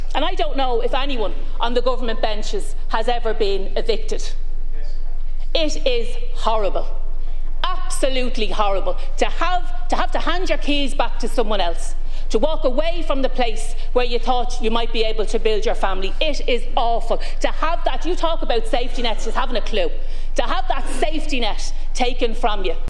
During the debate Sinn Féin TD Louise O’Reilly said the government has no idea what people facing eviction are going through: